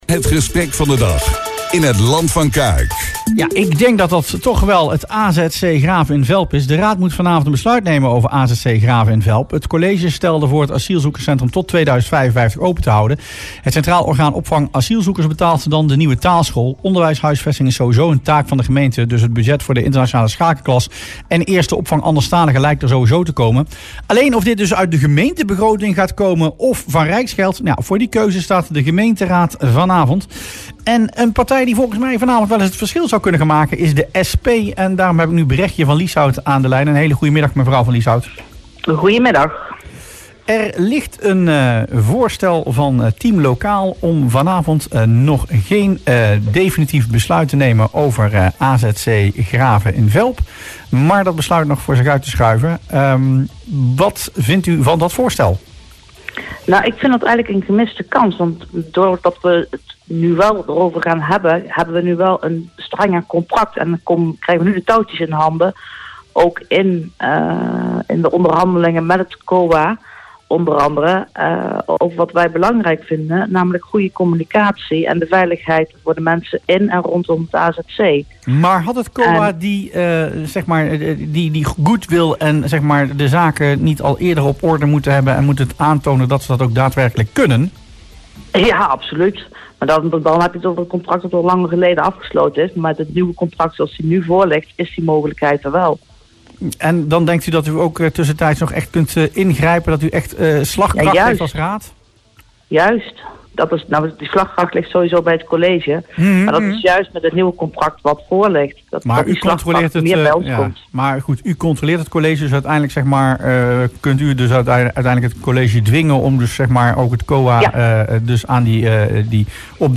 SP-fractieleider Bregje van Lieshout in Rustplaats Lokkant